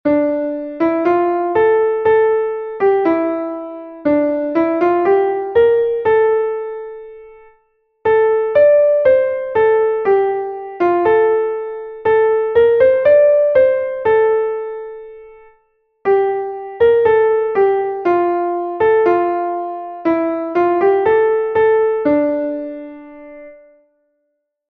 Bertso melodies - View details   To know more about this section
Gabonetakoa
A-B-C